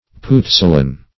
Puzzolan \Puz"zo*lan\